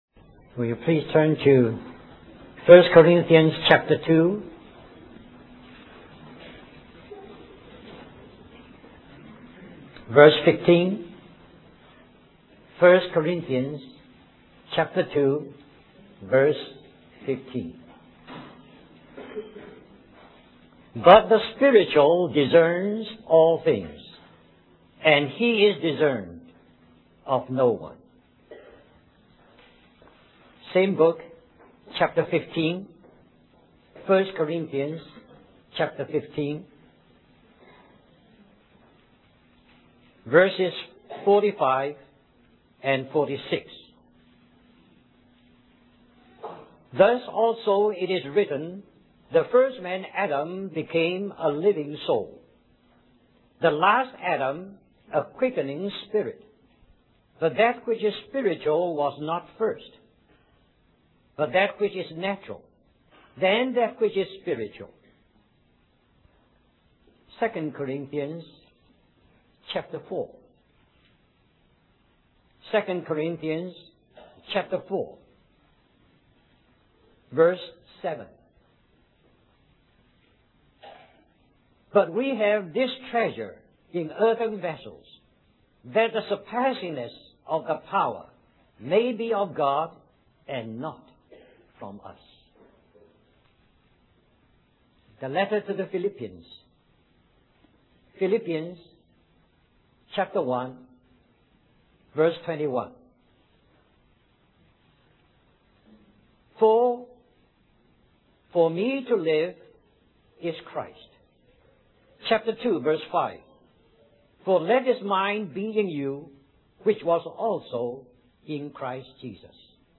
A collection of Christ focused messages published by the Christian Testimony Ministry in Richmond, VA.
1994 Harvey Cedars Conference Stream or download mp3 Summary This message is also printed in booklet form under the title